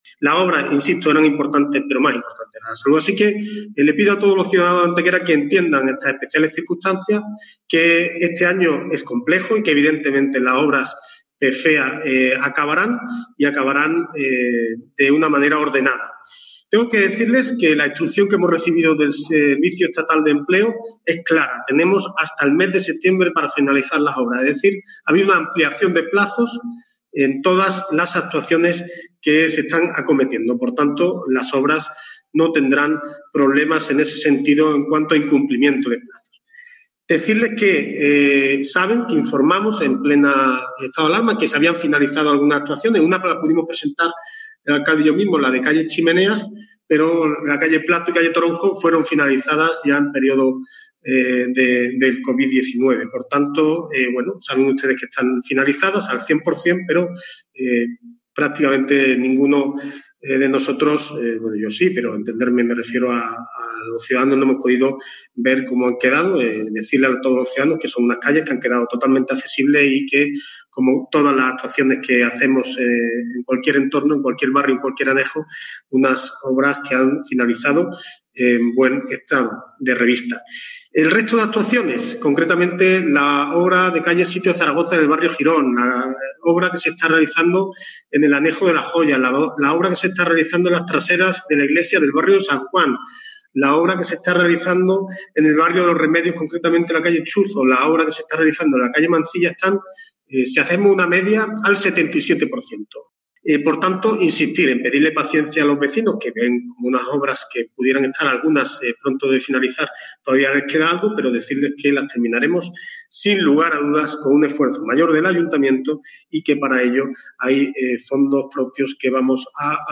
El alcalde de Antequera, Manolo Barón, y el concejal José Ramón Carmona han comparecido en la mañana de hoy martes ante los medios de comunicación en una rueda de prensa telemática que ha servido para exponer el trabajo que se lleva desarrollando en las últimas semanas en el Área de Obras así como la situación tanto de los proyectos incluidos en el PFEA 2019 como en el del 2020.
Cortes de voz